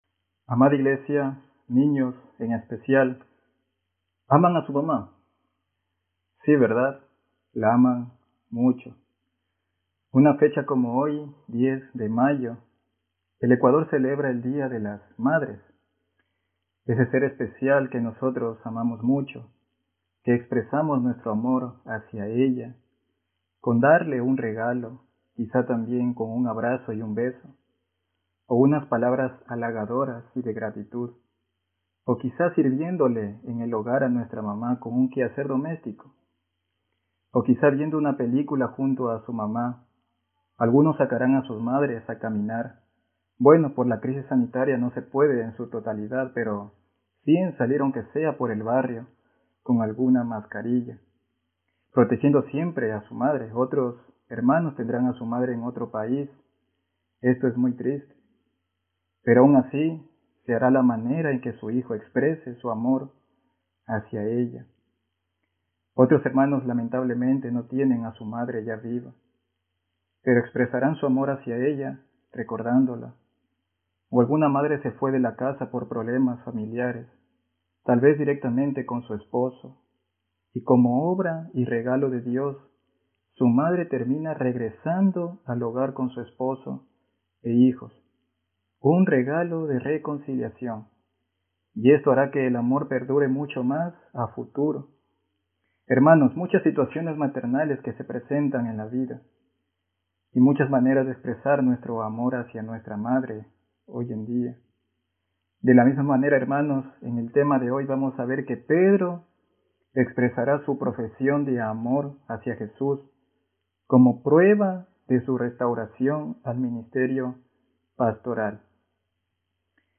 Juan 21:15-19 Tipo: Sermón Bible Text